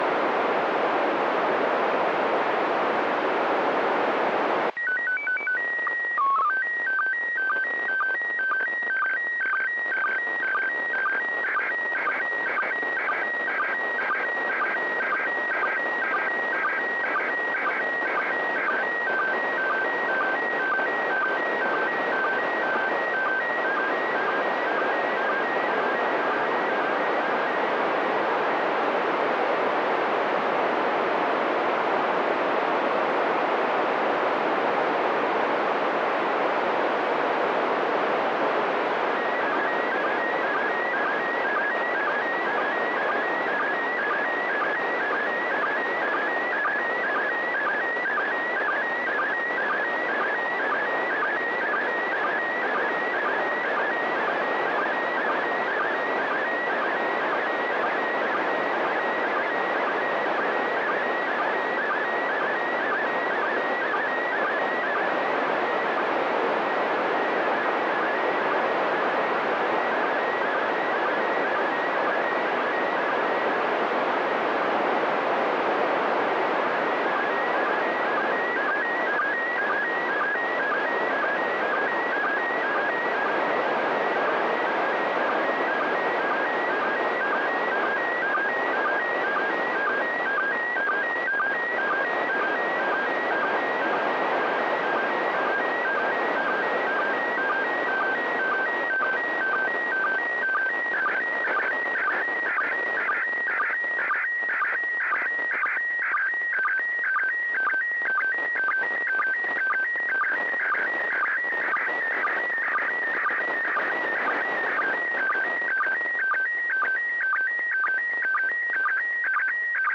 This was with a stationary vertical whip antenna though.
I actually still have audio recordings of the signal.